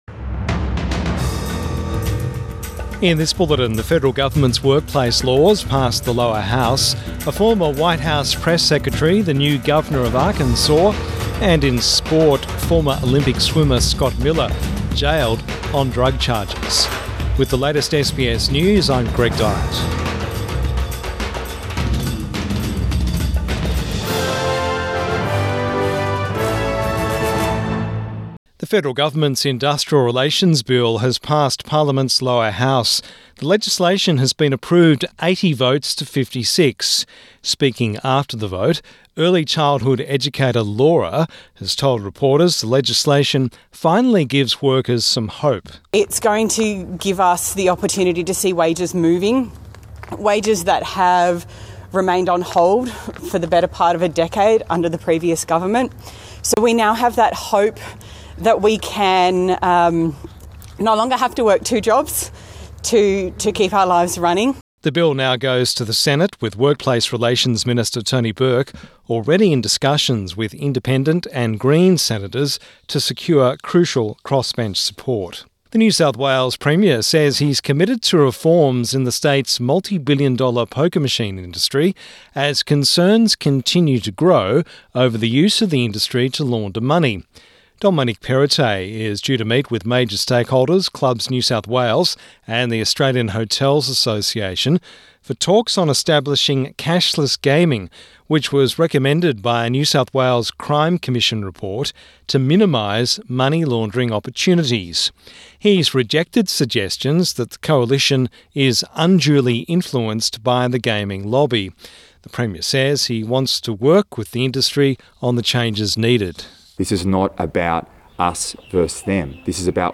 Evening News Bulletin 10 November 2022